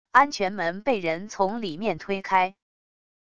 安全门被人从里面推开wav音频